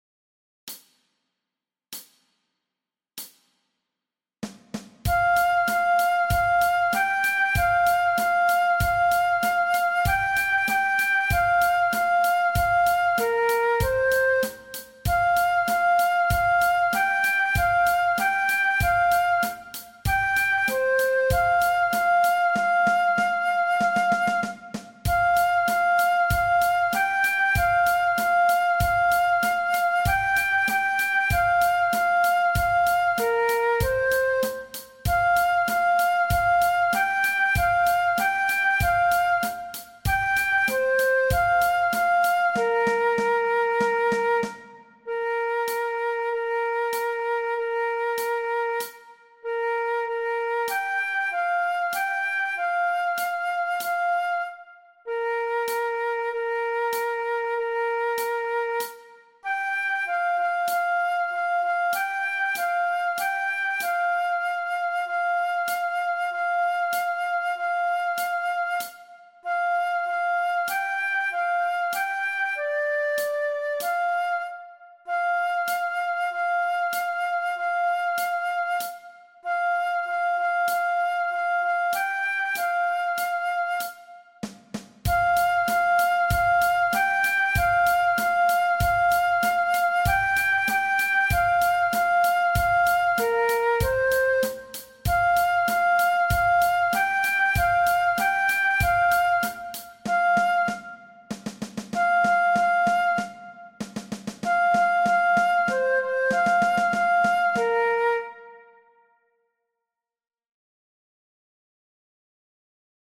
Aangepaste oefening NT 6.1 Dwarsfluit
Dwarsfluit aangepast